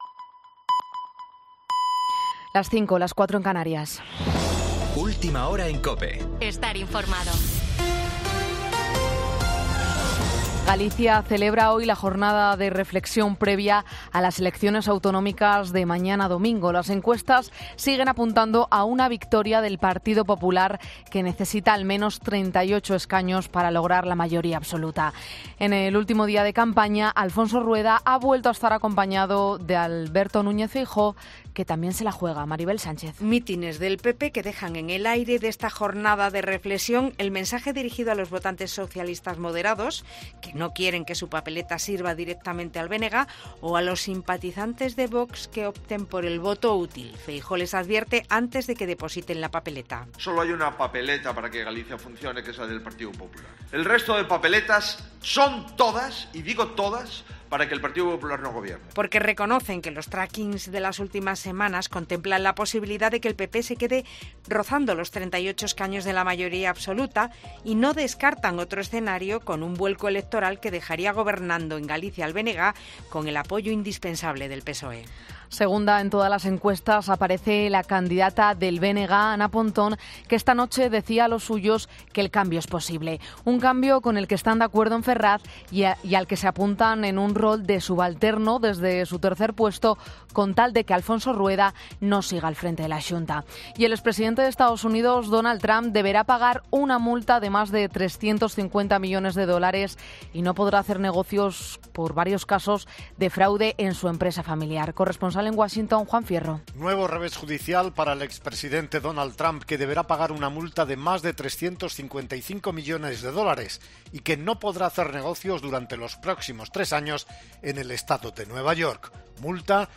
AUDIO: Boletín 05.00 horas del 17 de febrero de 2024